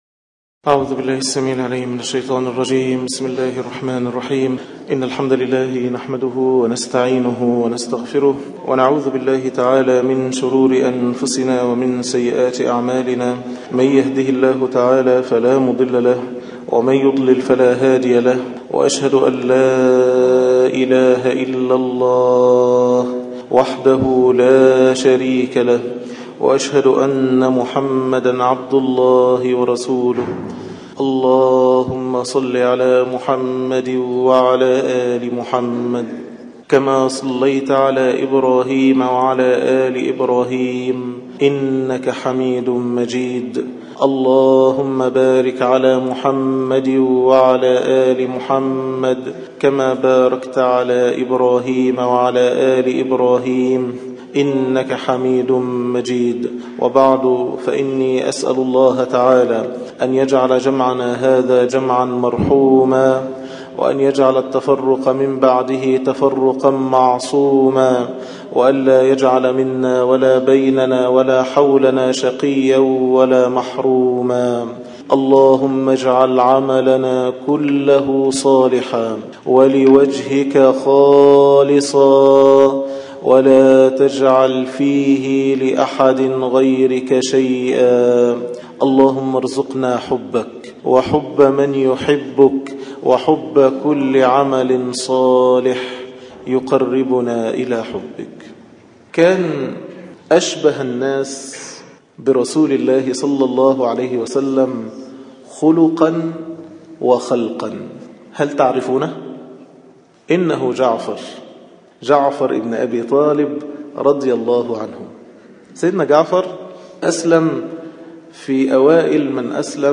khotab-download-83341.htm